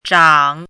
“仉”读音
zhǎng
仉字注音：ㄓㄤˇ
国际音标：tʂɑŋ˨˩˦
zhǎng.mp3